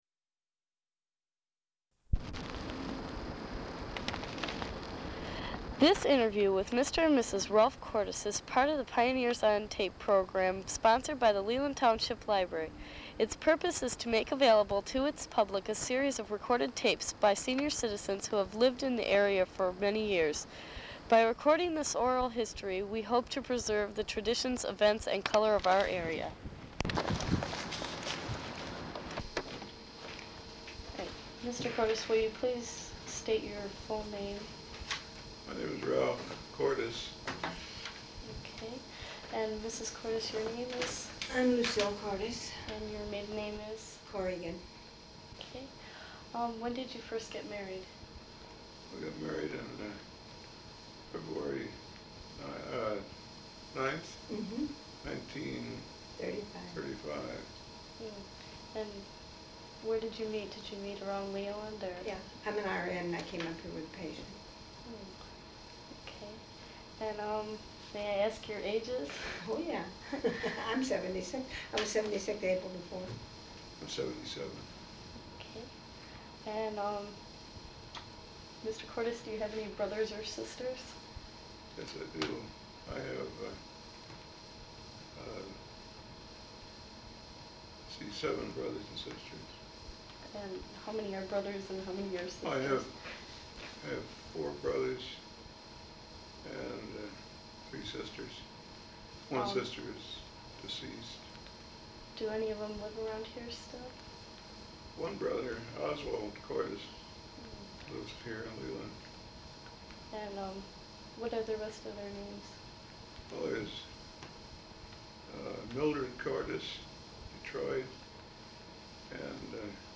Tape, Audio Cassette.
Oral History